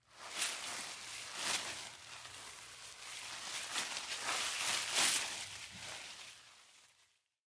The sound of a possum walking through bushes and rustling.
• Category: Sounds of possum